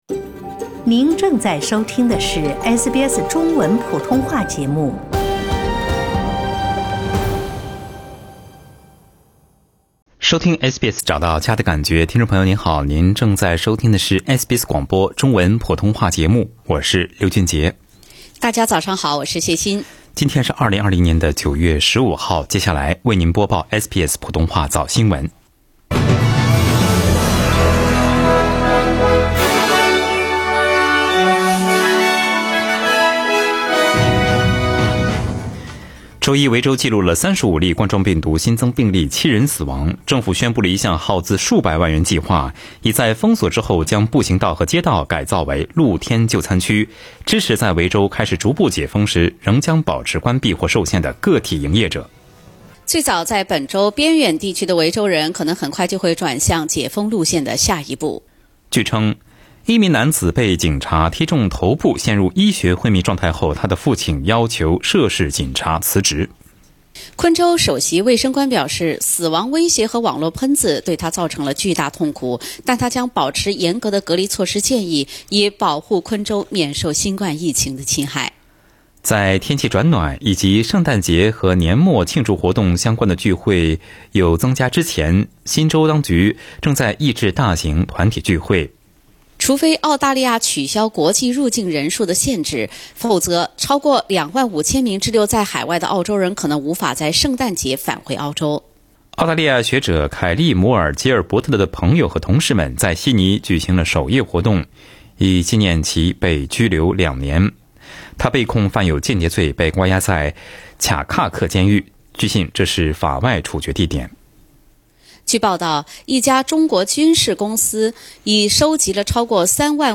SBS早新闻（9月15日）